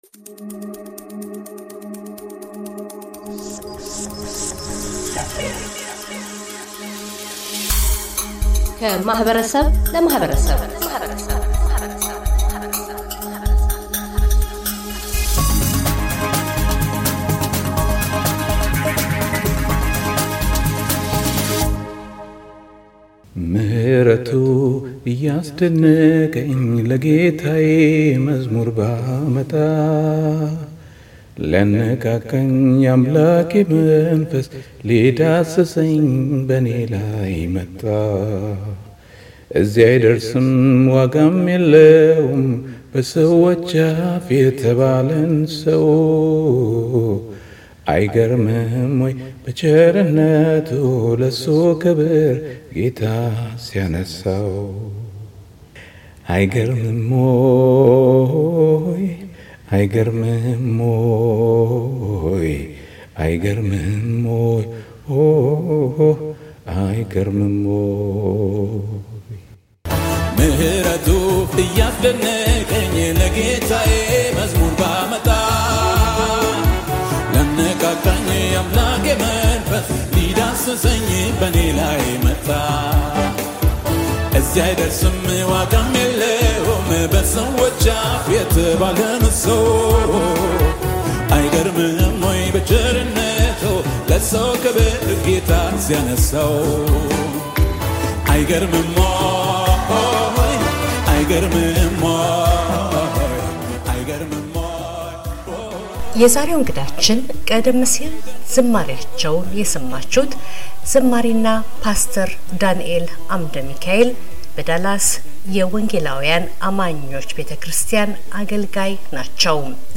በዝማሬ ህይወታቸውም ስምንት ሙሉ አልበሞችን የሰሩ ሲሆን ጥቂቶችንም በድምጻቸው አሰምተውናል ።